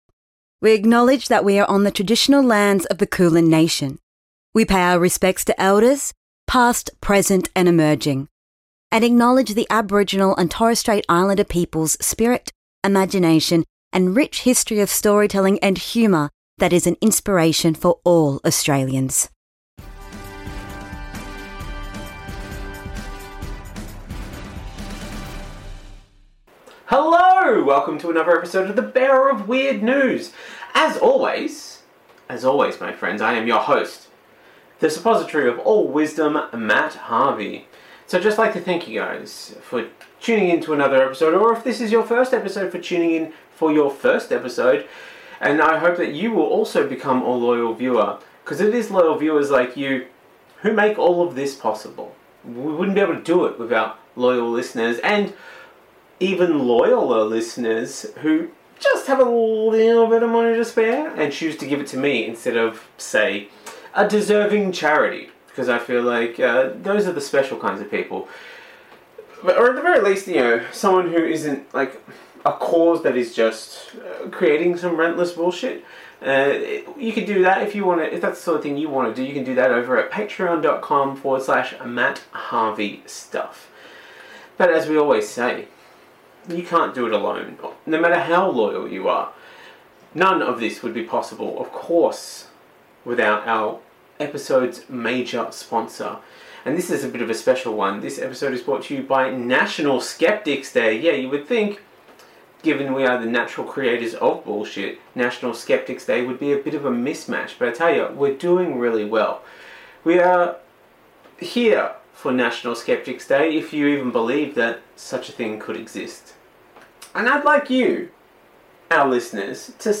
Australian news round-up